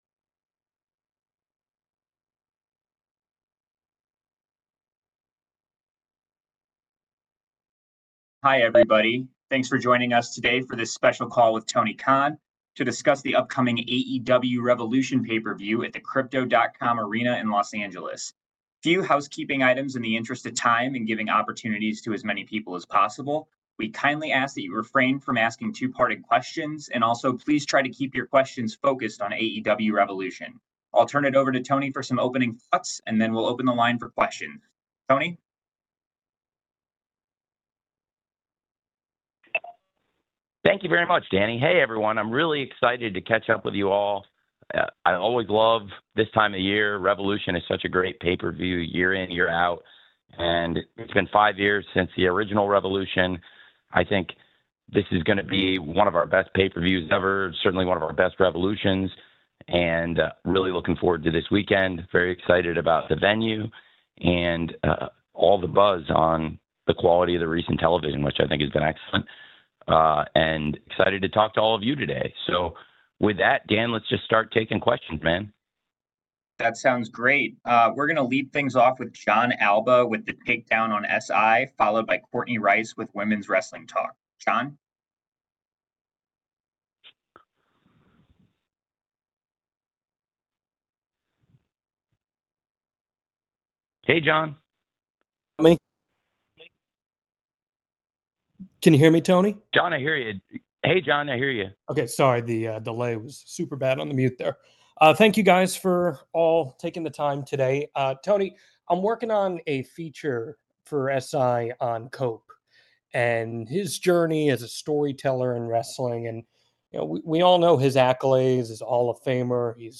Media call with Tony Khan about AEW Revolution 2025, the reason AEW TV is better these days, Cope, Toni Storm, cuts and more.